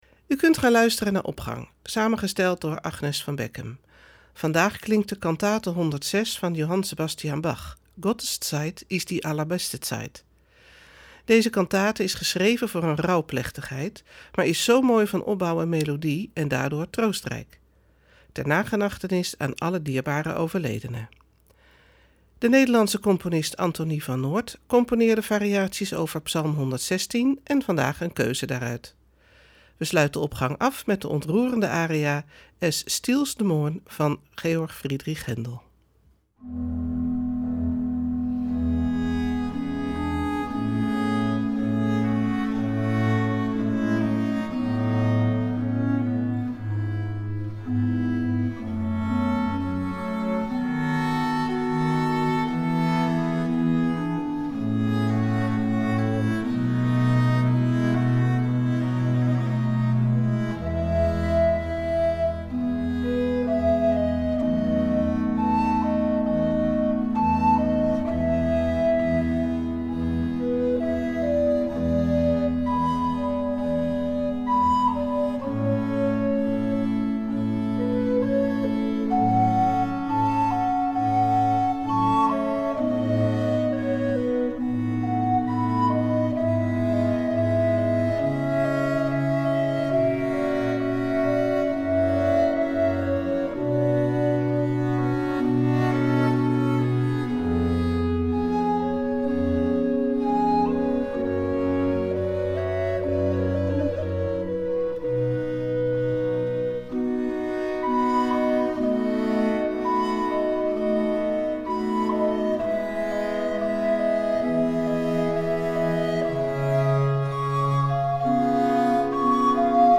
Opening van deze zondag met muziek, rechtstreeks vanuit onze studio.
Vandaag klinkt de cantate 106 van Johann Sebastian Bach, Gottes Zeit ist die allerbeste Zeit. Deze cantate is geschreven voor een rouwplechtigheid, maar is daarnaast werkelijk zo mooi van opbouw en melodie en daardoor troostrijk.